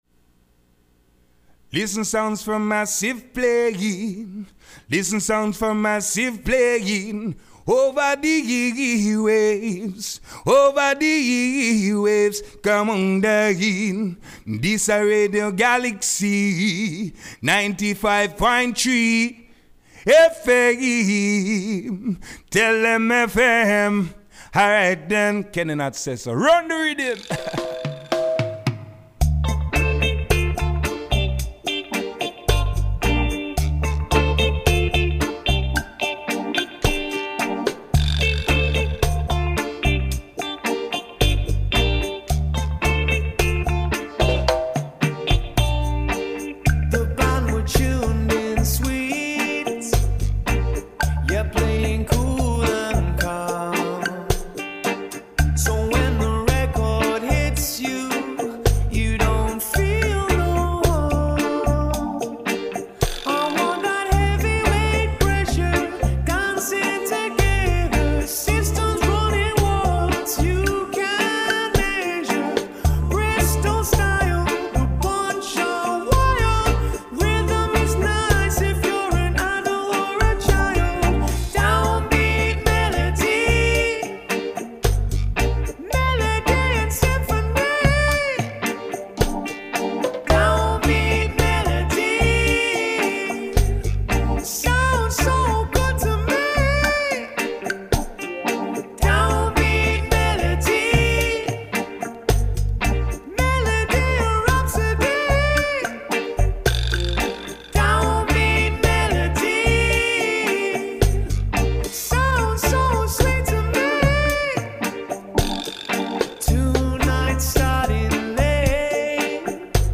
reggaephonique